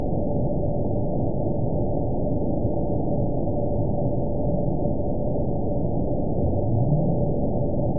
event 922757 date 03/26/25 time 02:44:19 GMT (2 months, 3 weeks ago) score 9.31 location TSS-AB01 detected by nrw target species NRW annotations +NRW Spectrogram: Frequency (kHz) vs. Time (s) audio not available .wav